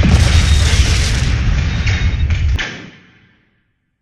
missilehit.ogg